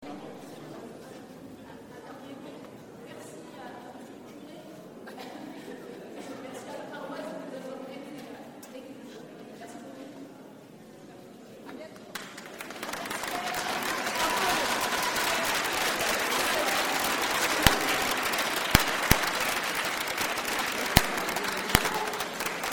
Concert à l’église : Après les images, la vidéo, voici le son..